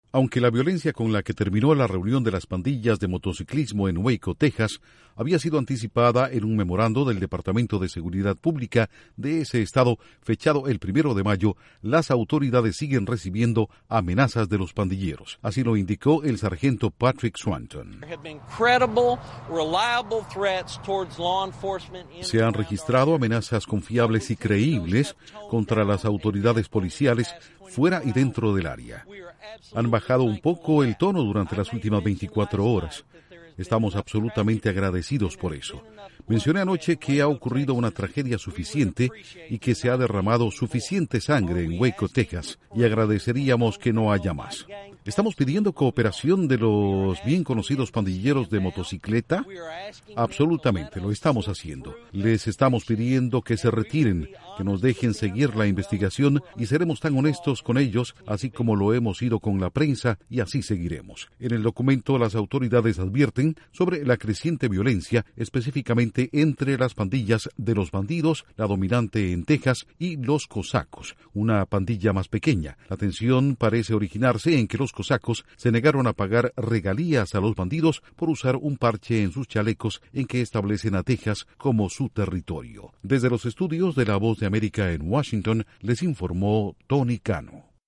Autoridades de Waco reciben nuevas amenazas de pandillas que se enfrentaron en esa ciudad el fin de semana. Informa desde los estudios de la Voz de América en Washington